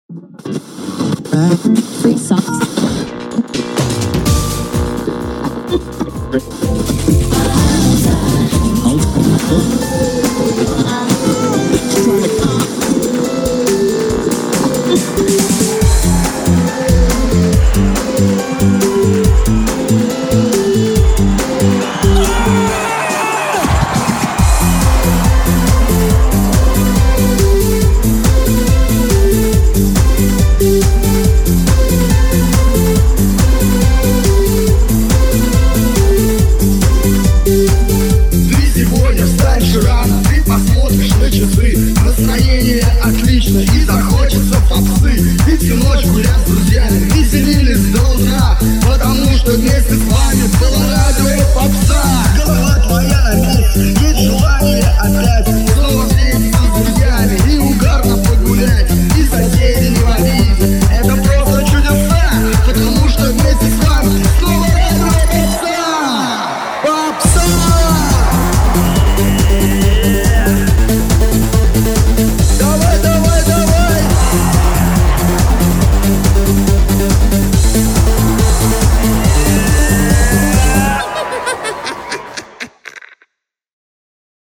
• Жанр: Танцевальная